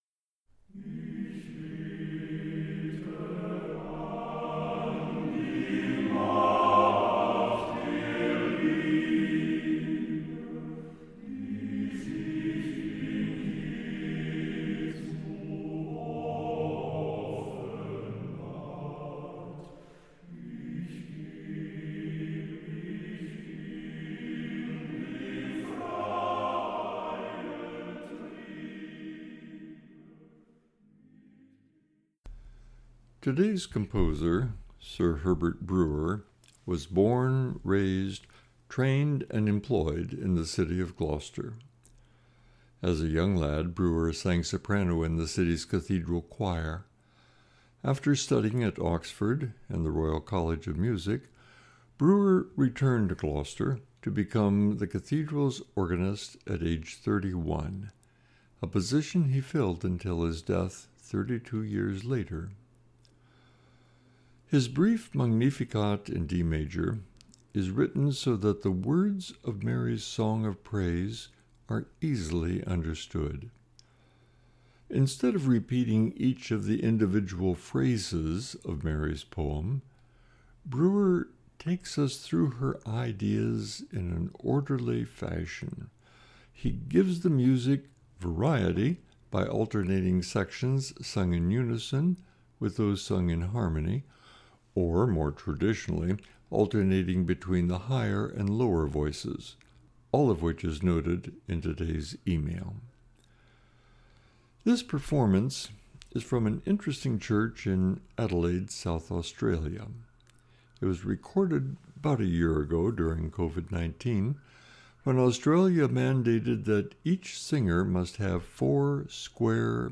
2022-01-10 Meditation for Monday in the 1st Week of Ordinary Time (Brewer - Magnificat in D)